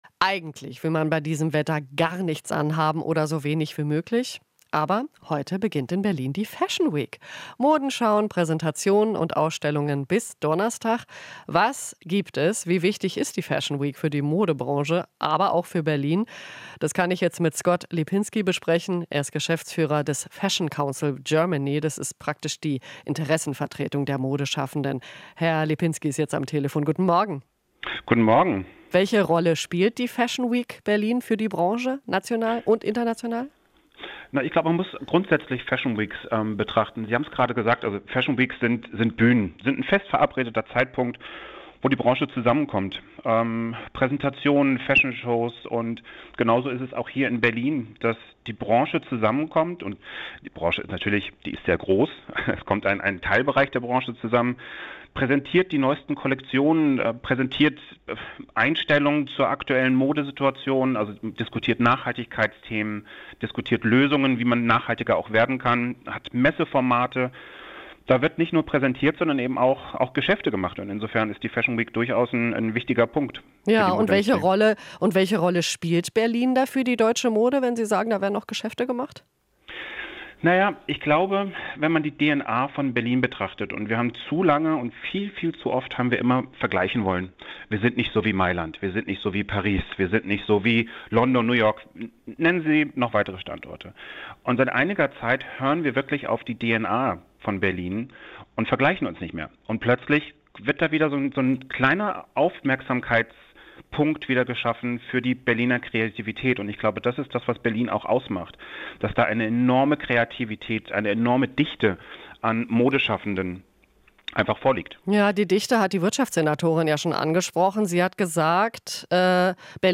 Interview - Fashion Council Germany: Modetreffen als Bühnen für die Branche nutzen